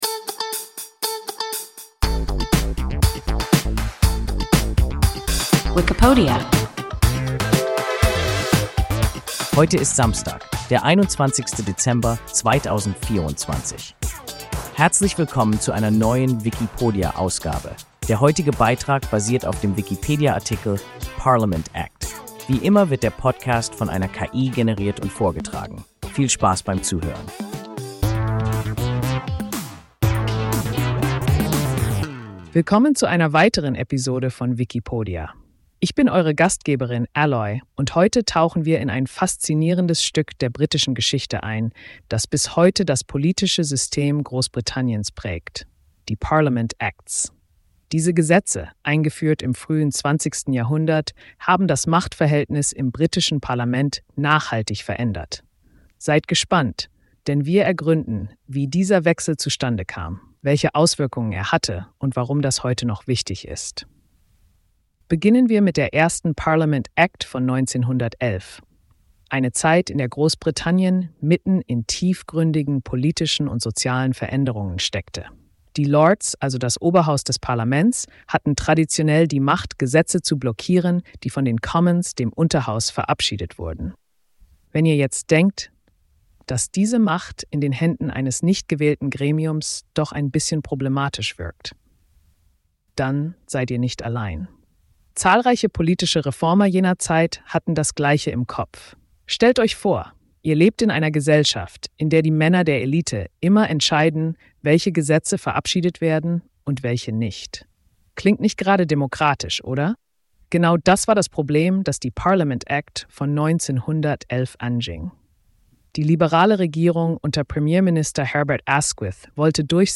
Parliament Act – WIKIPODIA – ein KI Podcast